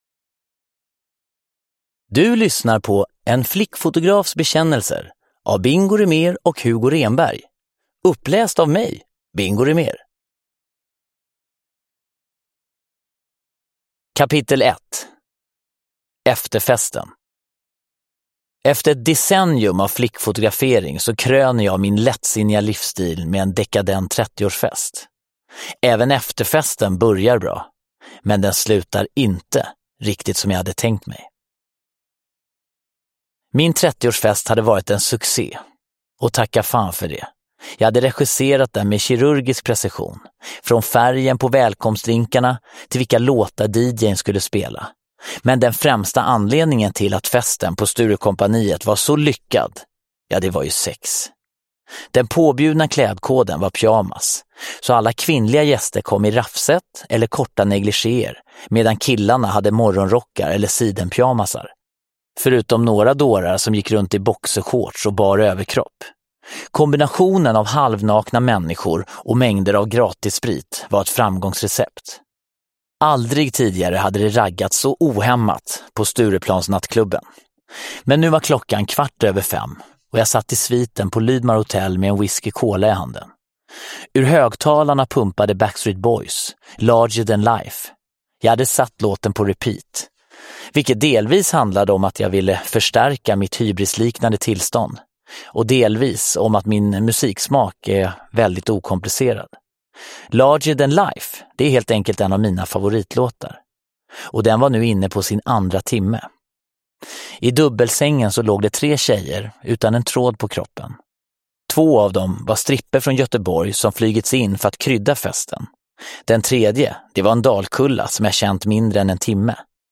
En flickfotografs bekännelser – Ljudbok – Laddas ner
Uppläsare: Bingo Rimér